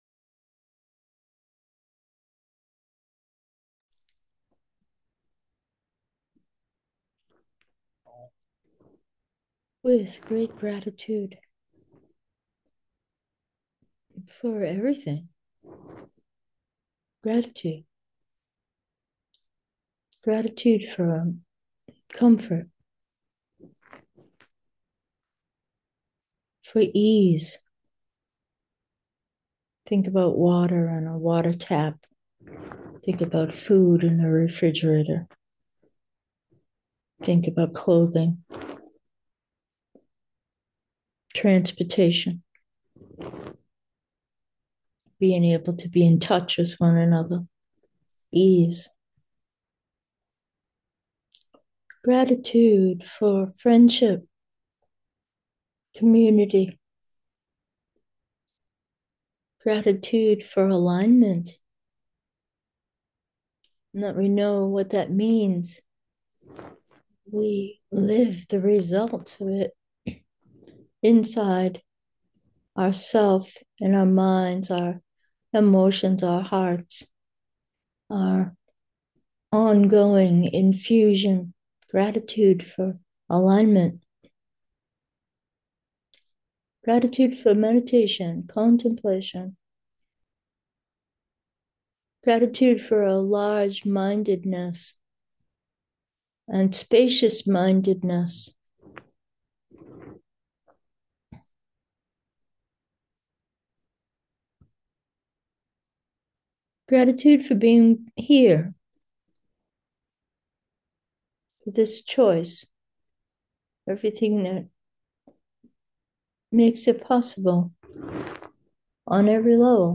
Meditation: simple presence 2, gratitude